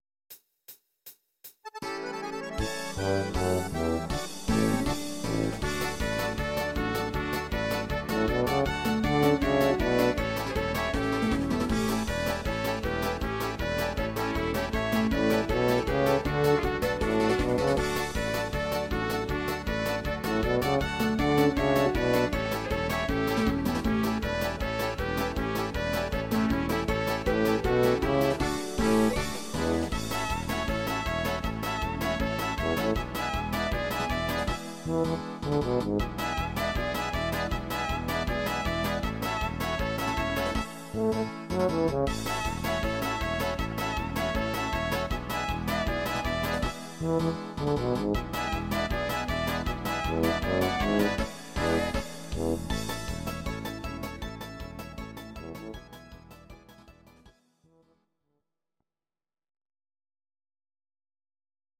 instr.